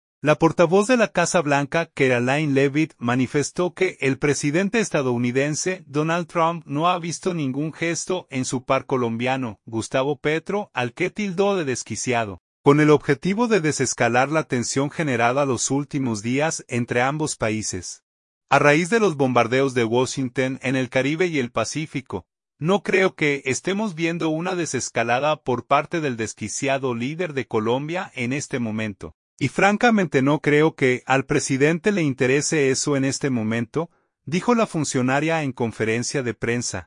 "No creo que estemos viendo una desescalada por parte del desquiciado líder de Colombia en este momento, y francamente no creo que al presidente le interese eso en este momento", dijo la funcionaria en conferencia de prensa.